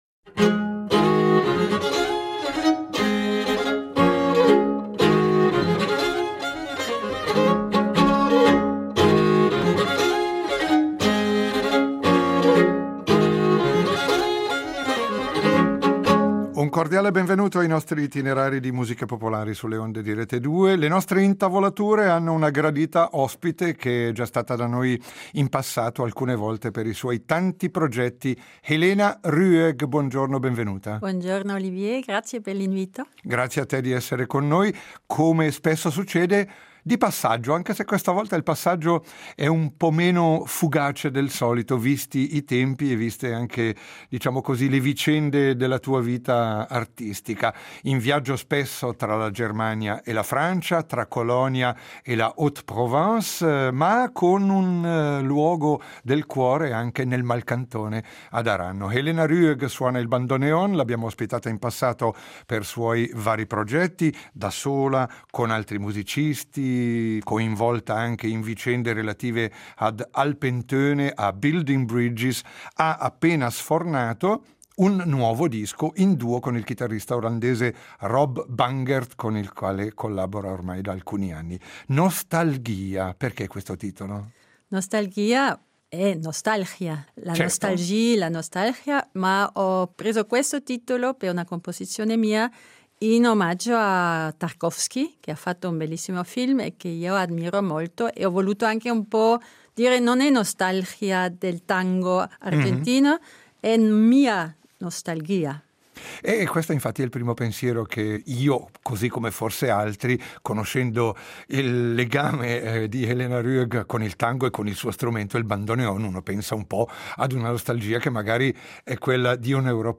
inTAVOLAture
ritroviamo ai nostri microfoni